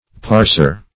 parser \pars"er\, n.